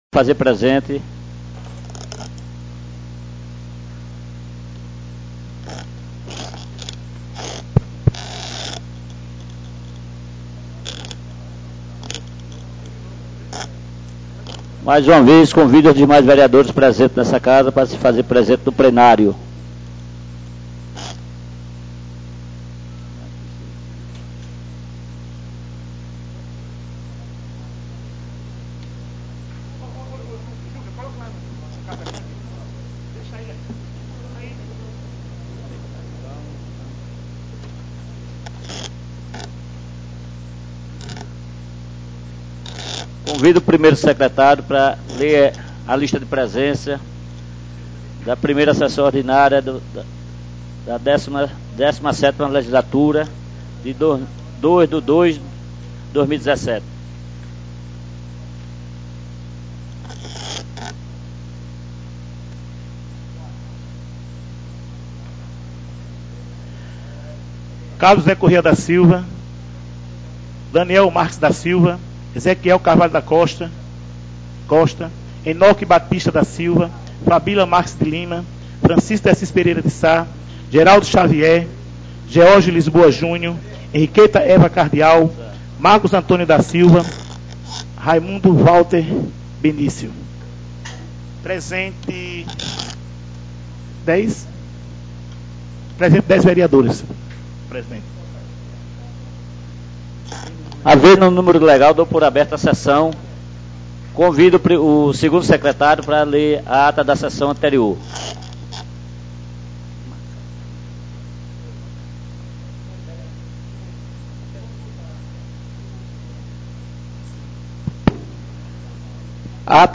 Nº 01/2017 DATA 02/02/2017 TIPO Ordinárias DESCRIÇÃO 1ª Sessão Ordinária da 17ª (Décima Sétima) Legislatura (2017 - 2020) no dia 02 de fevereiro de 2017.